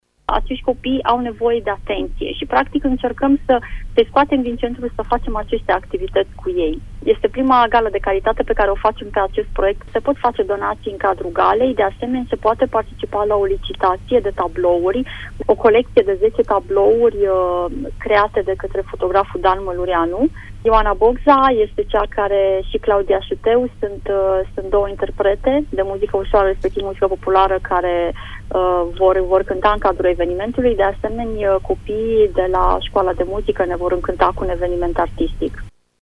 Gala, care va avea loc a ora 18,00, își propune să sprijine copiii din centrele de plasament, a declarat în emisiunea Pulsul Zilei de la RTM